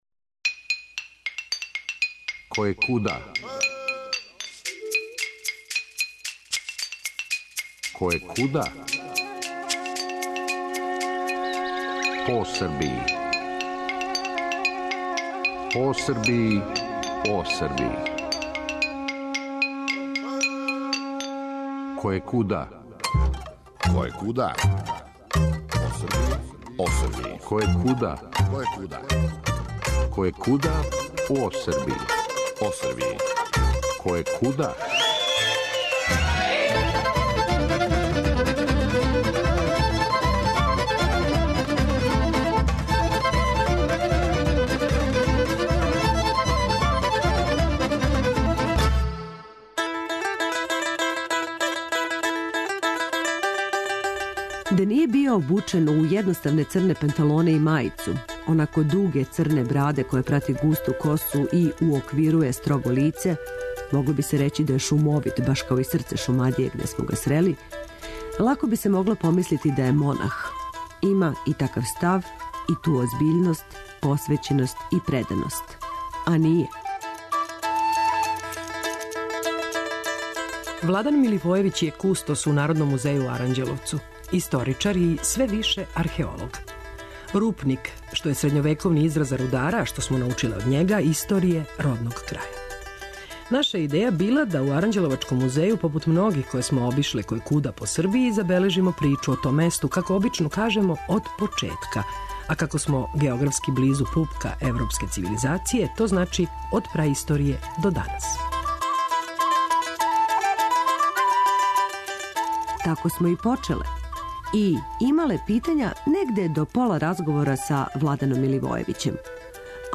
А онда смо - приметићете сигурно то и сами - заћутале. И само слушале.